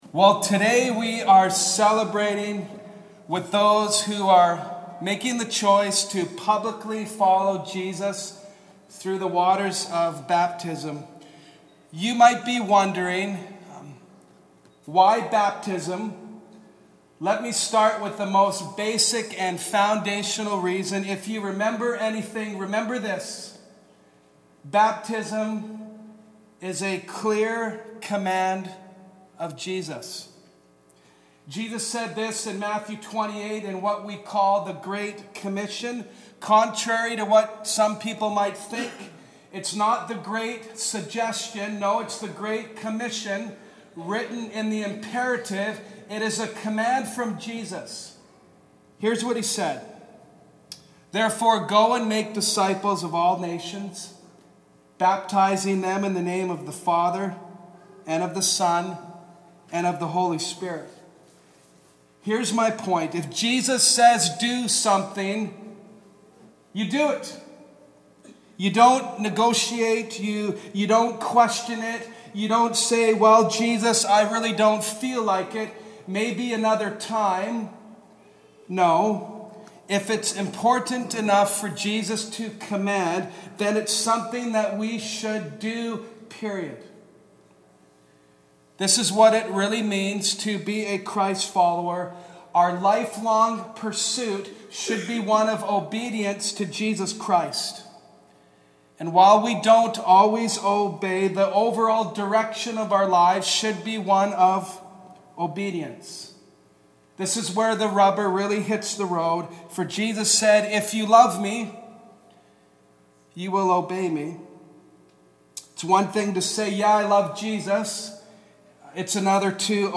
Baptism Devotional